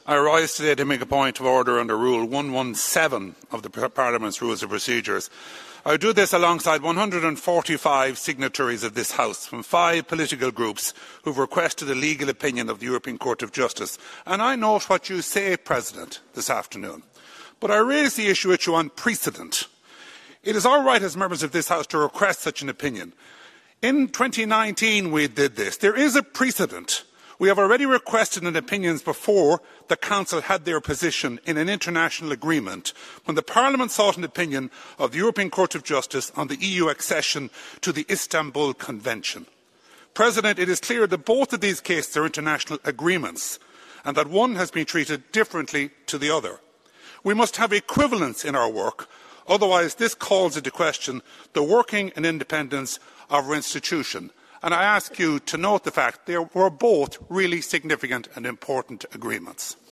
Mr Mullooly challenged that on the floor of the parliament……….